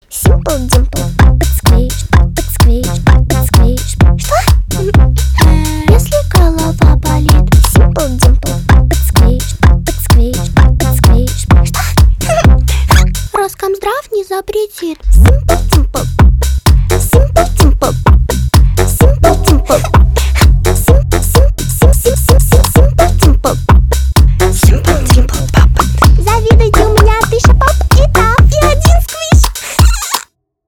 • Качество: 320, Stereo
детский голос
поп-музыка
Шуточная мелодия для любителей оригинального жанра